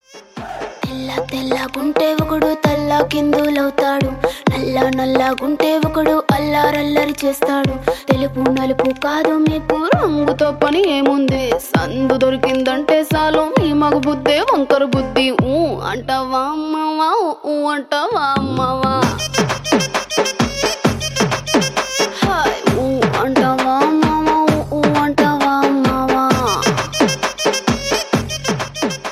This energetic track became a massive hit across India.